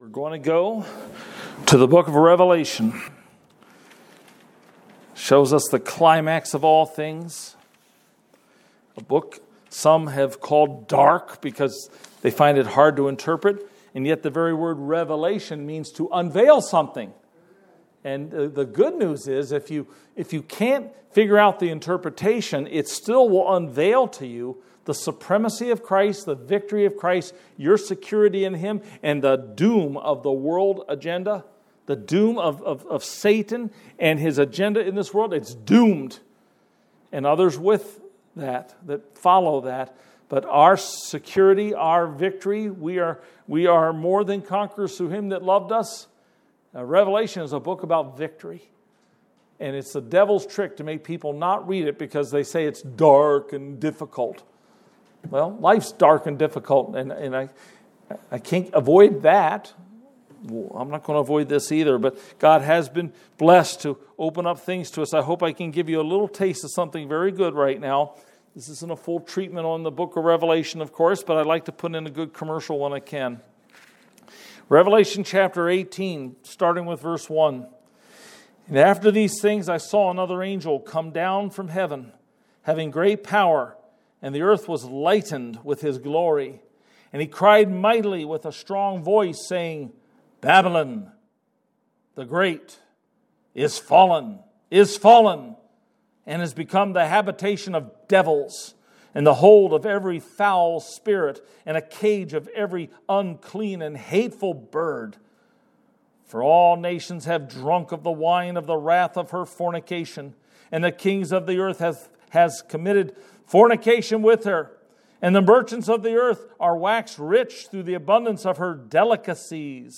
Welcome to Northland Bible Church